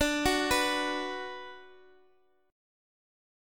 B5/D chord
B-5th-D-x,x,x,7,7,7-8.m4a